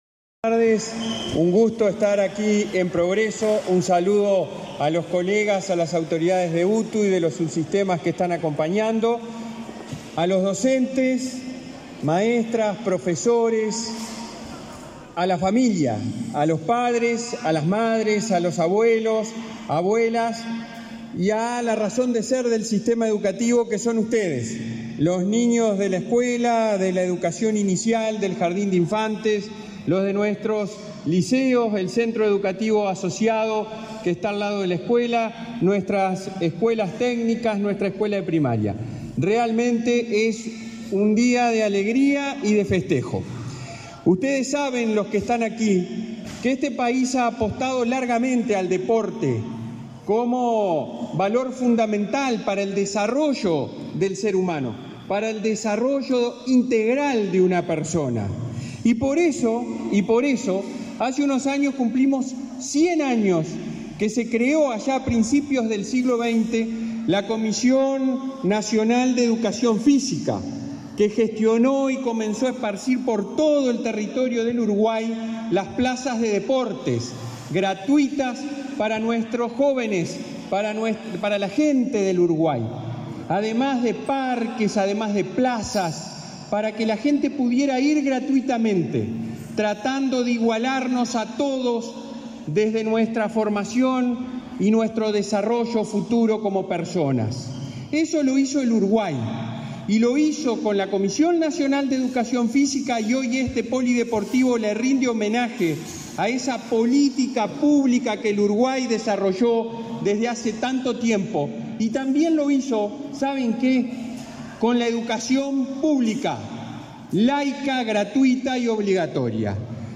Palabras del presidente de la ANEP, Robert Silva
En el evento, el presidente de ANEP, Robert Silva, realizó declaraciones.